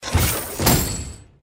diving_treasure_drop_off.ogg